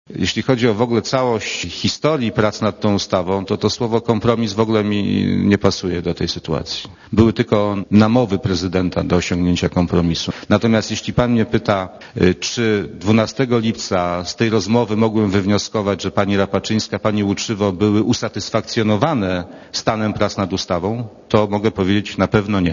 zadane mu podczas przesłuchania przed komisją śledczą, czy w